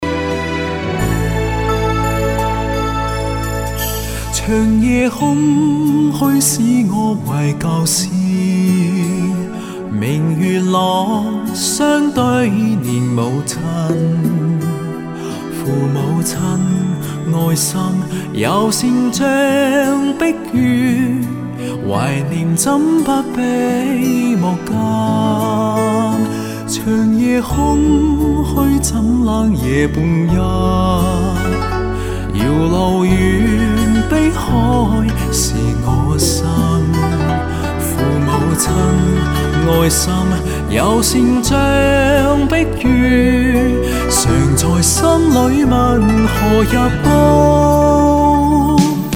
它的自带话放特点属于那么解析力强 直白 染色少 下面我就给你展示 下我搜罗到的 天琴座自带话放 录制的作品 截取一小段给你听 your_browser_is_not_able_to_play_this_audio